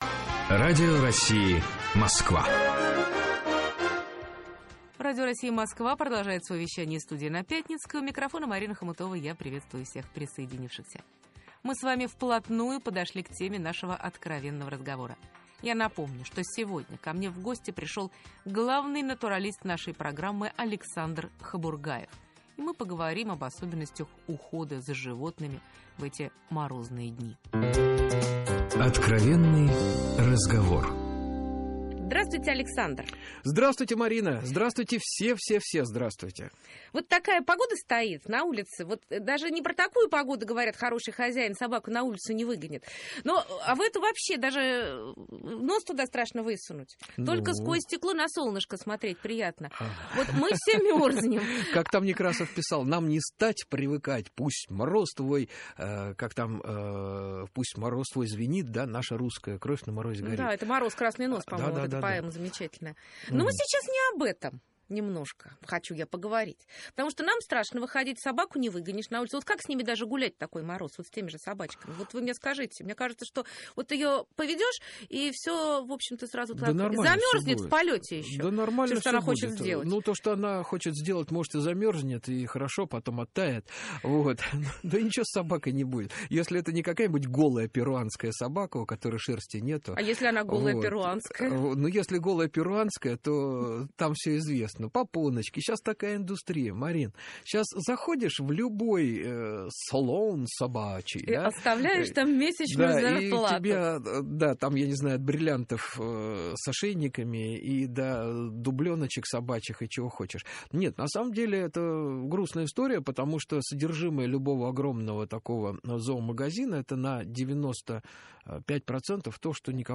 Источник: Радио России.